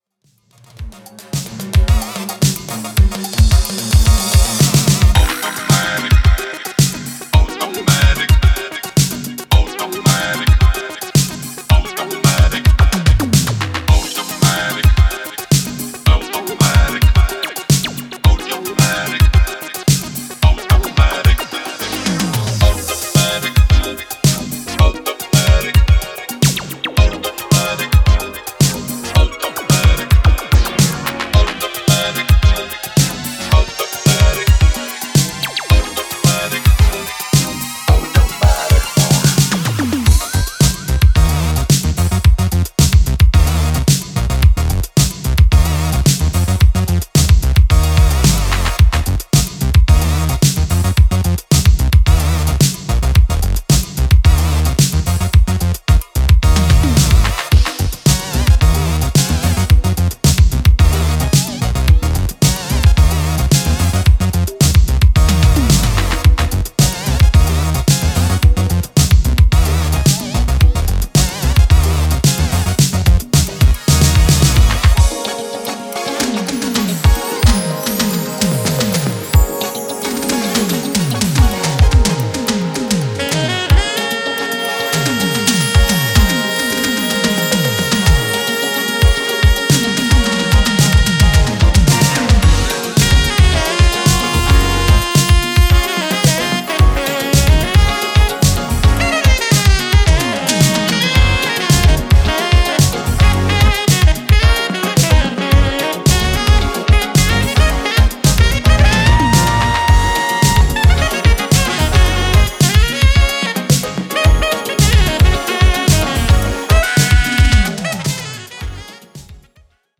New school funk boogie